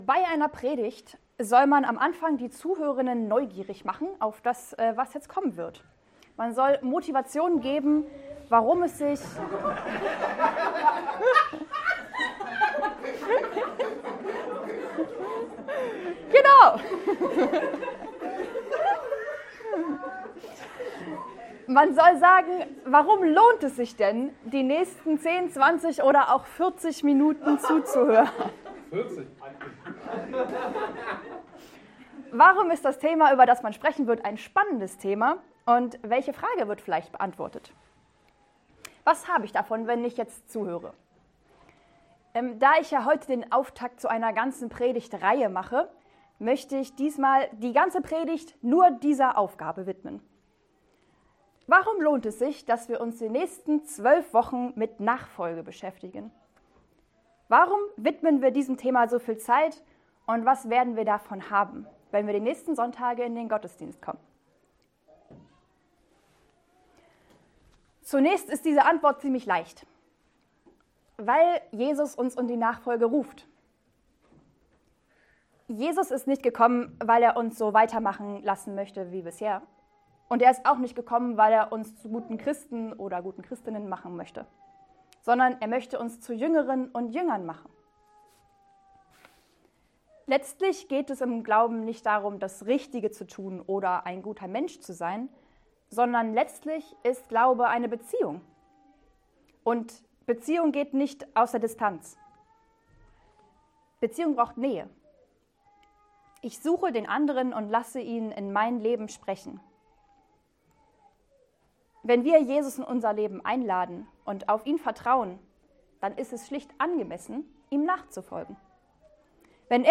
Eine predigt aus der serie "GreifBar+."